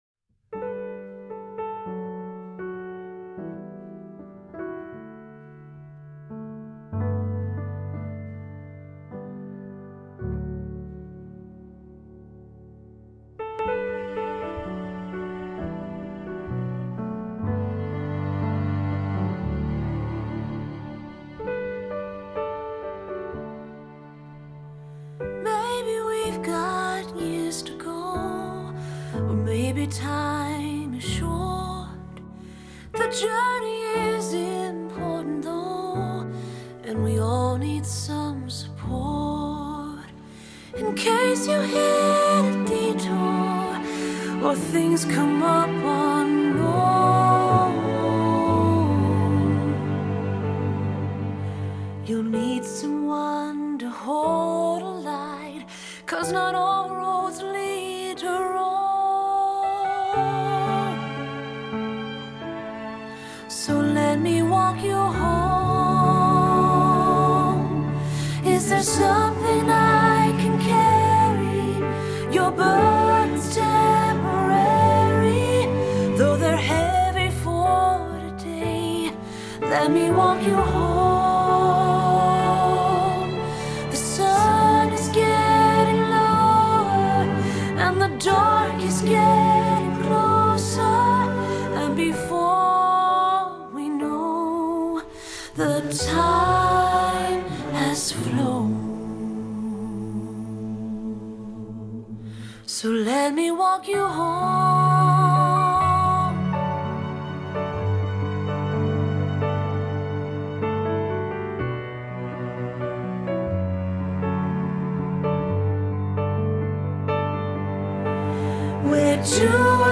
Celebration Choir Selections.
Duet